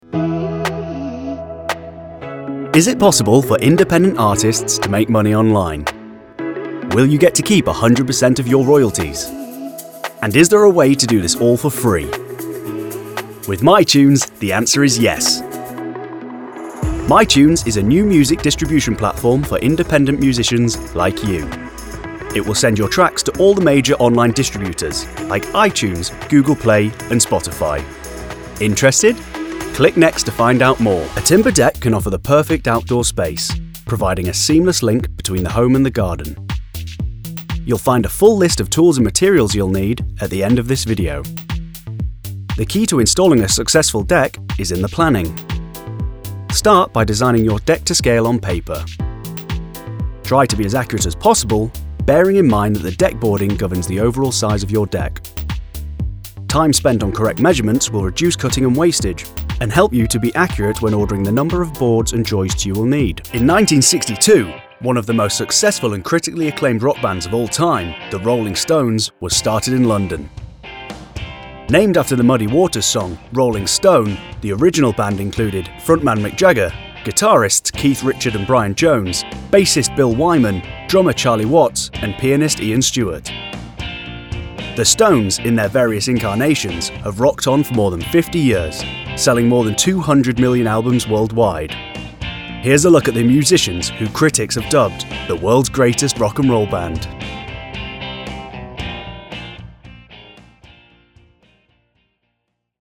Voiceover Demos
Narrative Demo